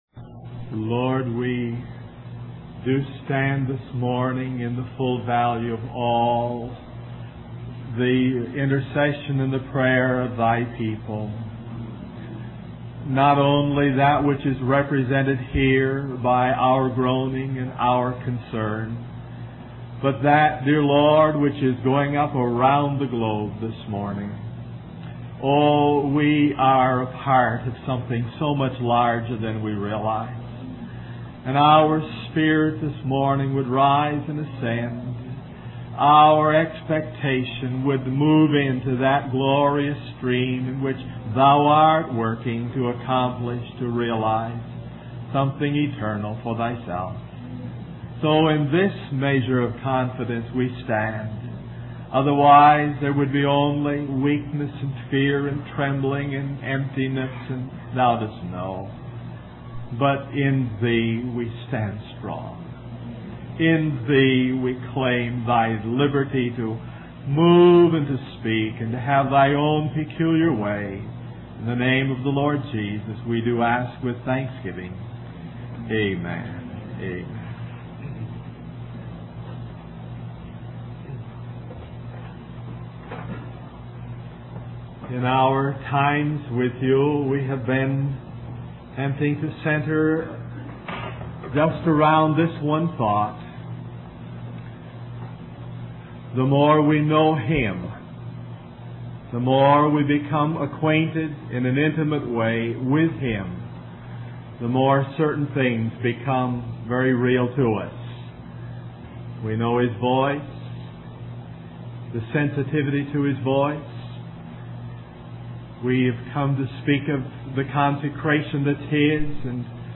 A collection of Christ focused messages published by the Christian Testimony Ministry in Richmond, VA.
Wabanna (Atlantic States Christian Convocation)